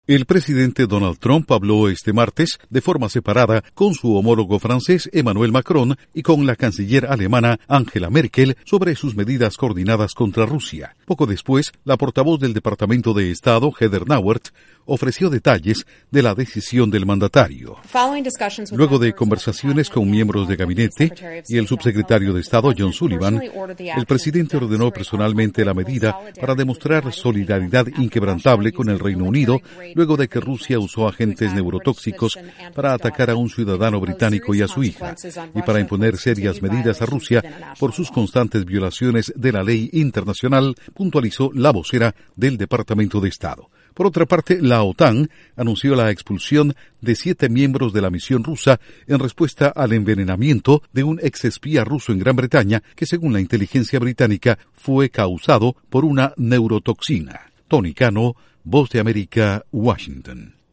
Incluye dos audios de Heather Nauert / Vocera del Departamento de Estado.